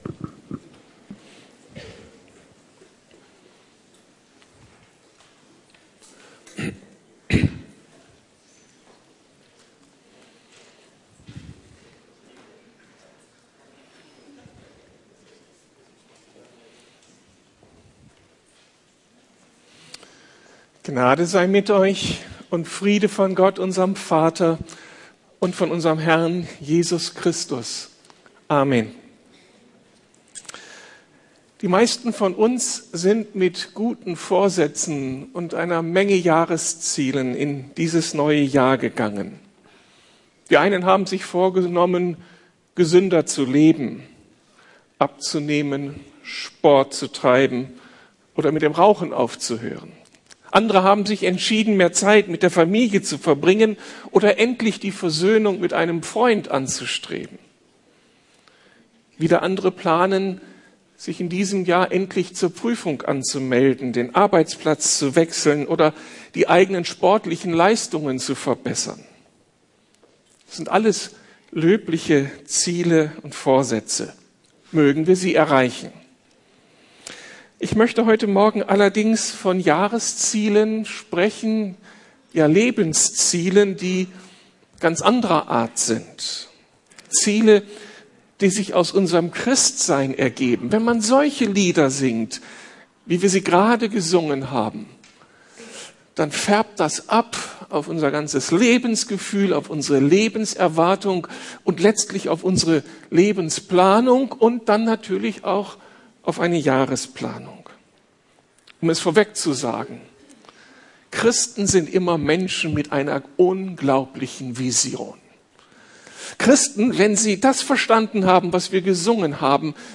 Ueber gute Vorsätze und Jahresziele aus biblischer Perspektive ~ Predigten der LUKAS GEMEINDE Podcast